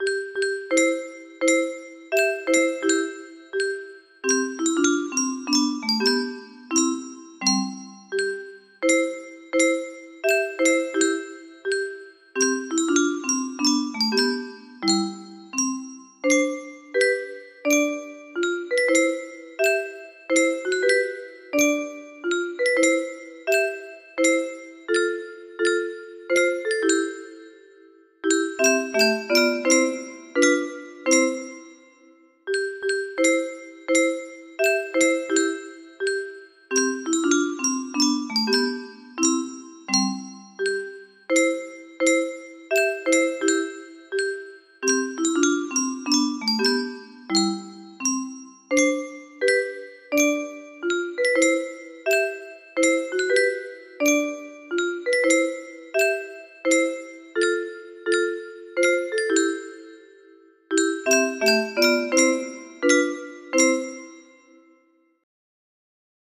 Die Gedanken sind Frei music box melody